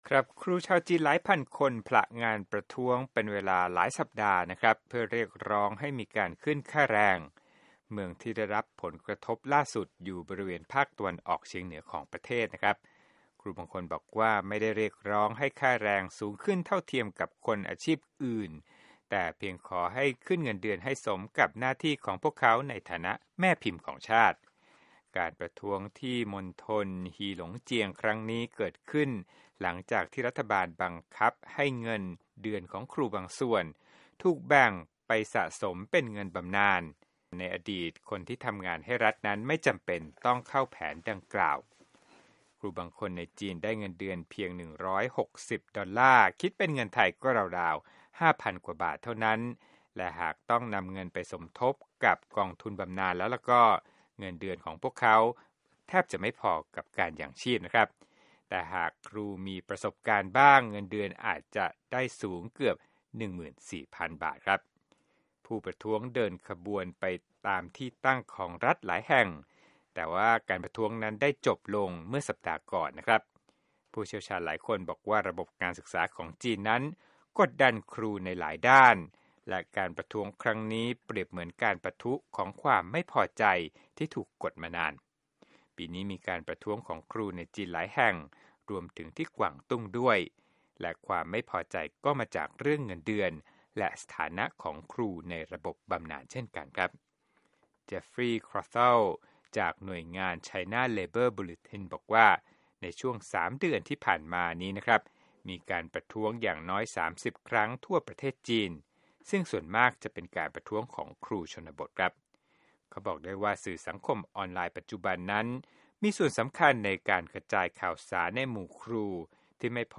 โปรดติดตามรายละเอียดจากคลิปเรื่องนี้ในรายการข่าวสดสายตรงจากวีโอเอ